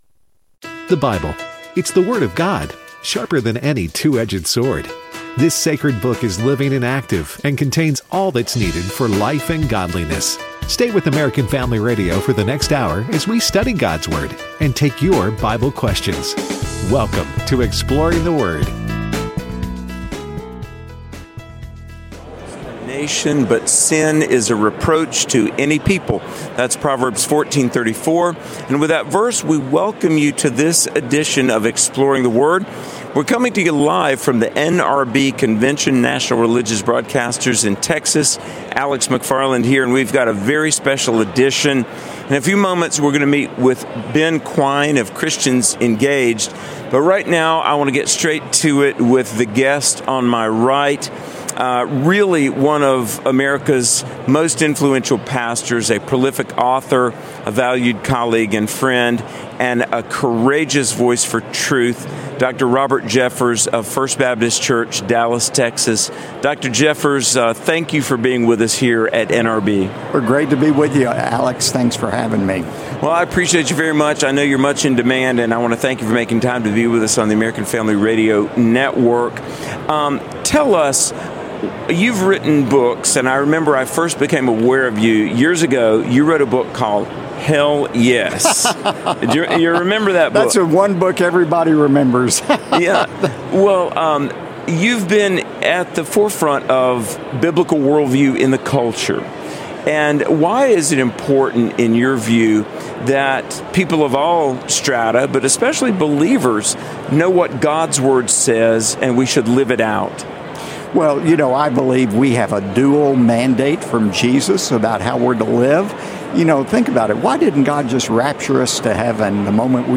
Live From The 2025 NRB Christian Media Convention